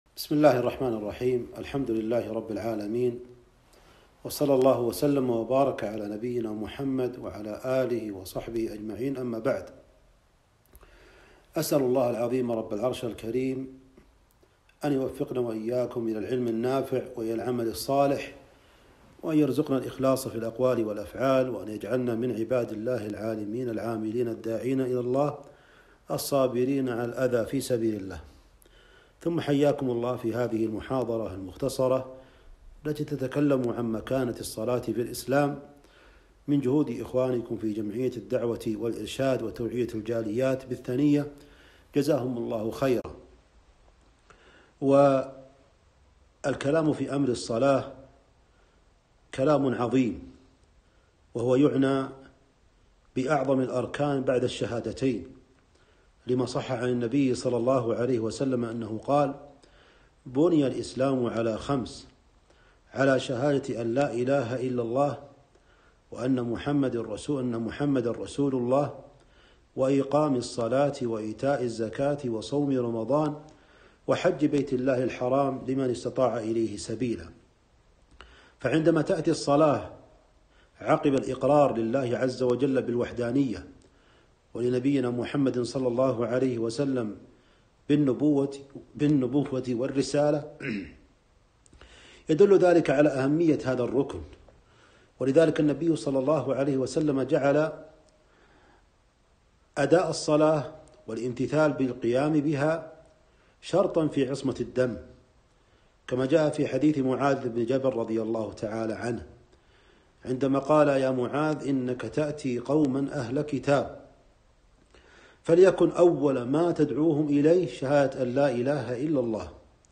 محاضرة - مكانة الصلاة في الإسلام